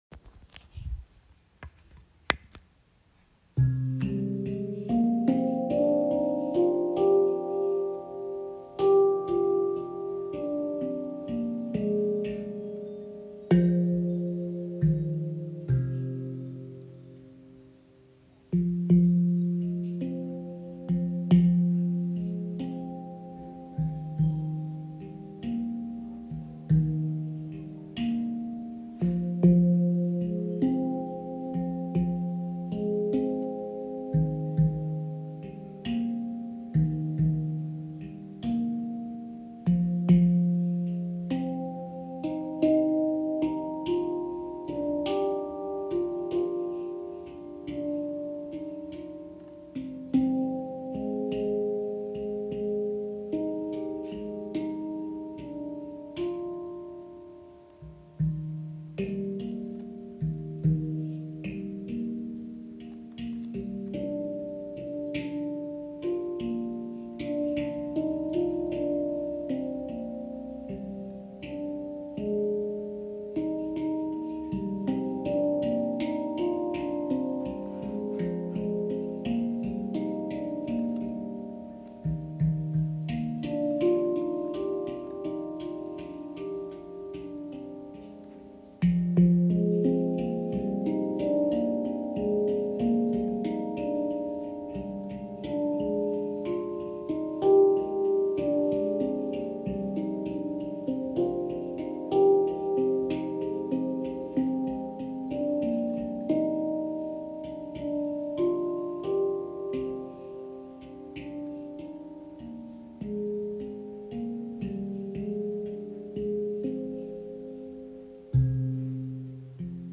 Die ShaktiPan in C Kurd verbindet warme, mystische Klänge mit sanftem Anschlag und eignet sich ideal für meditative Klangreisen und intuitive Improvisationen.
Töne: C (D# F)  G – G# – Bb – C – D – D# – F – G Klingt erdig, geheimnisvoll und öffnend – ideal für meditative Klangreisen und gefühlvolles Spiel.
Handpan ShaktiPan | C Kurd
Die C Kurd Stimmung klingt warm, offen und emotional – ideal für ruhige Improvisationen und meditative Klangräume.
Die C-Kurd-Stimmung umfasst die Töne: C (D# F ) G – G# – Bb – C – D – D# – F – G. Diese vielseitige Skala vereint Moll-Charakter mit orientalischer Tiefe und lädt zu intuitivem, gefühlvollem Spiel ein.